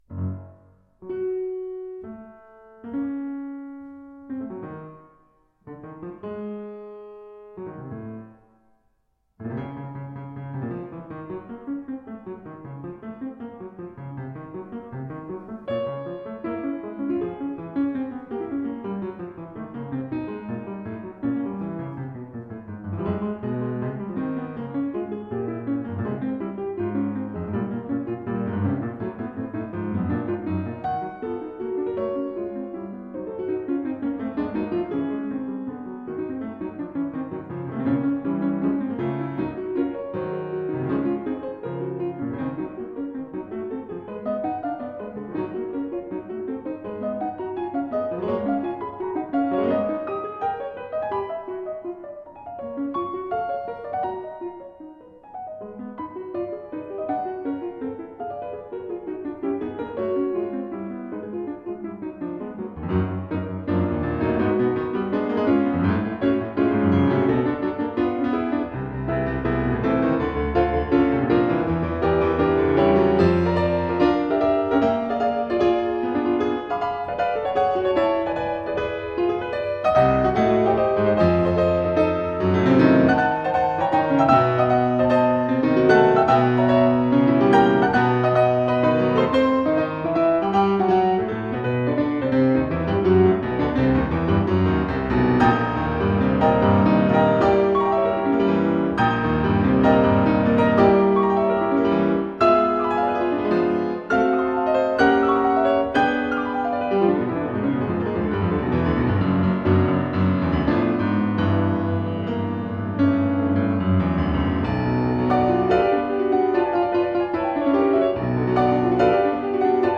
Enregistré les 6-9 janvier 2020, la Goillote, Vosne-Romanée.
Musicalement une référence.